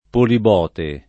[ polib 0 te ]